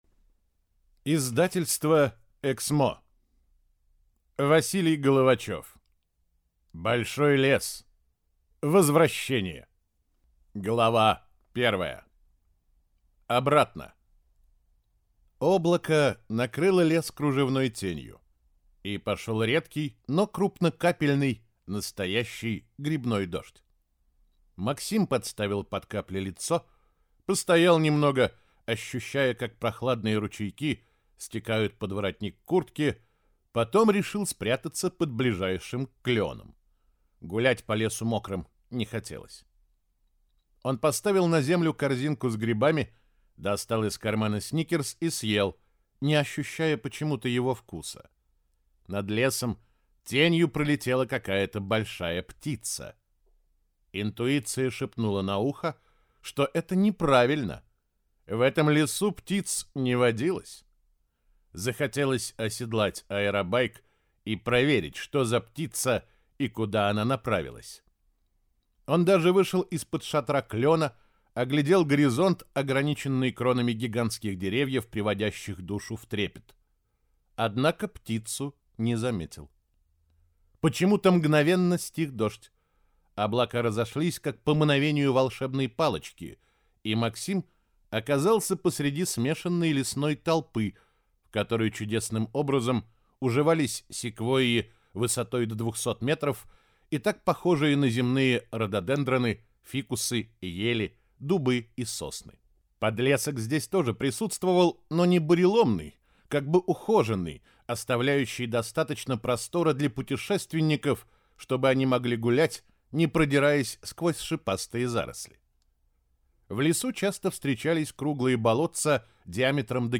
Аудиокнига Большой лес. Возвращение | Библиотека аудиокниг